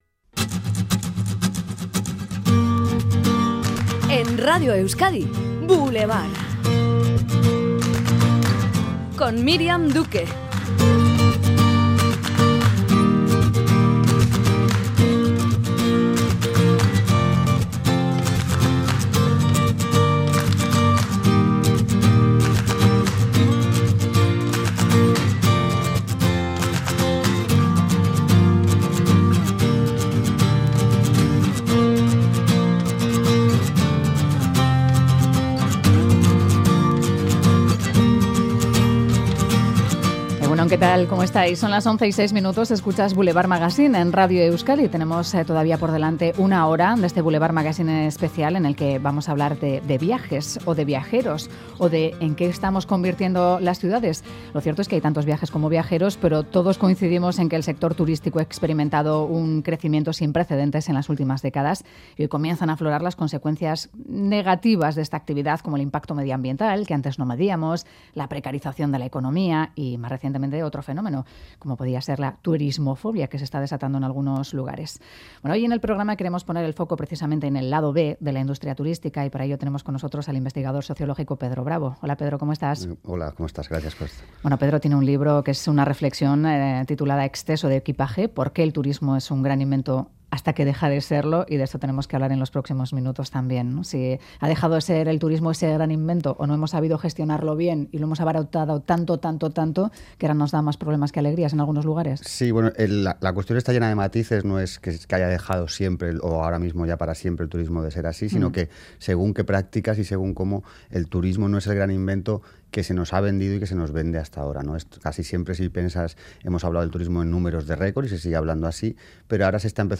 Charlamos sobre el futuro del turismo, de sostenibilidad y masificación